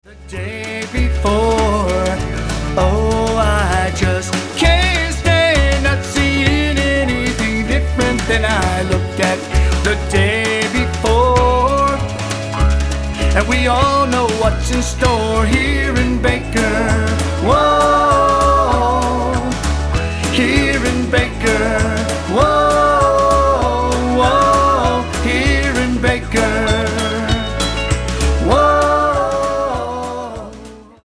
Jug Band 2 beat
guitar